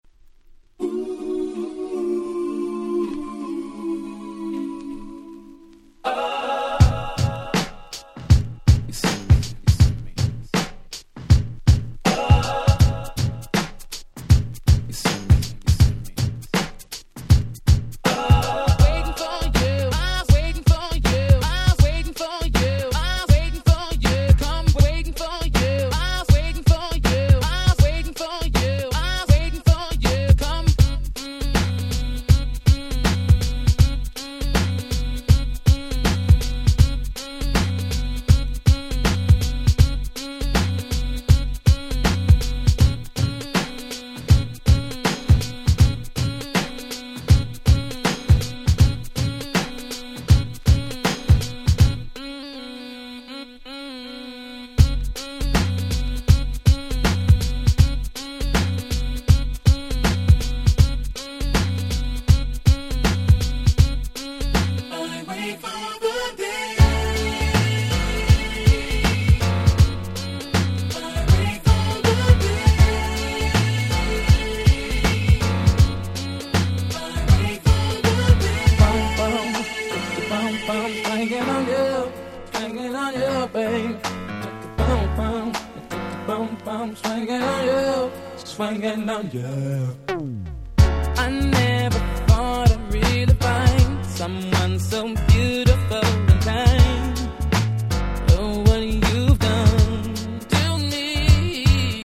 93' Nice Mid Dancer !!